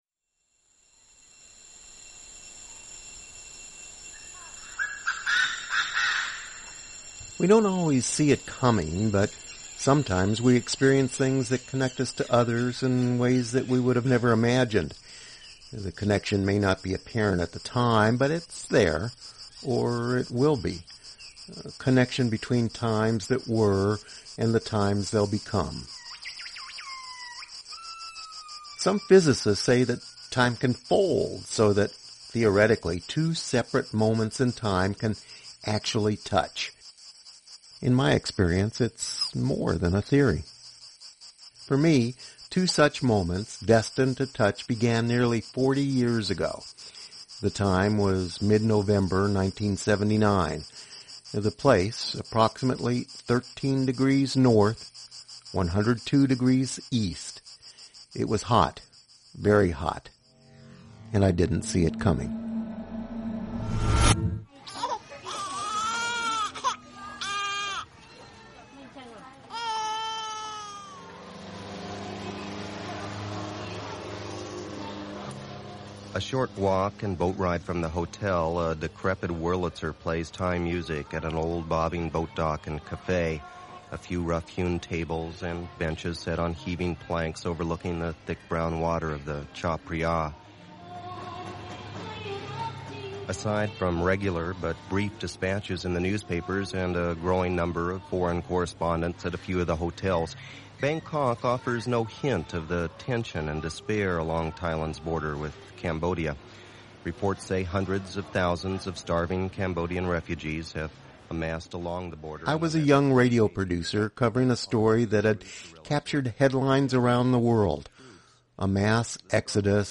An American journalist and three survivors of the tragedy, two men and one woman, recall the tragedy of the “killing fields” of Cambodia.